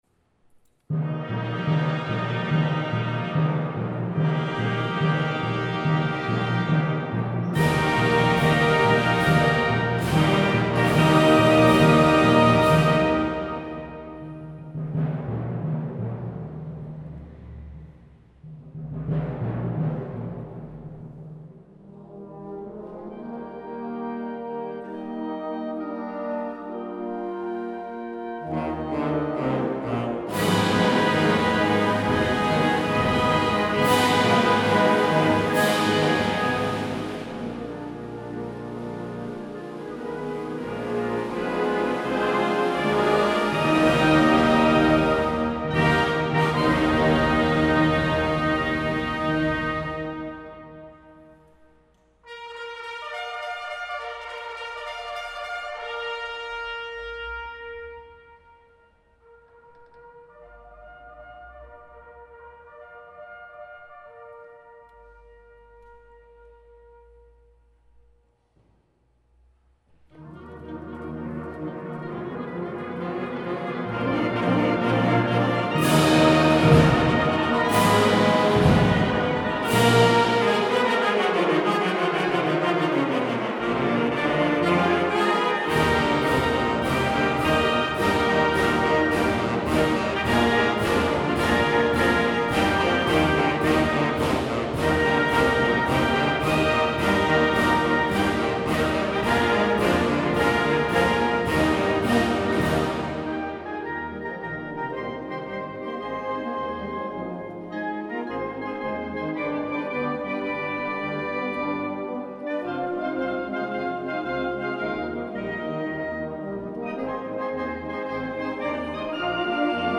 Música costarricense interpretada por la Banda Nacional de Cartago